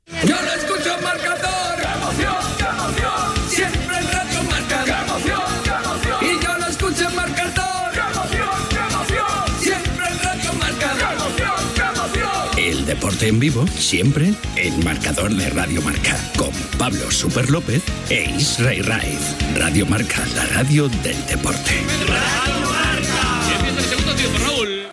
Promoció del programa.
Esportiu
FM